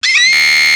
The dog's name is Pincil and this is the sound it makes
Pincil voice I don't know why it says "risas"
Risas.wav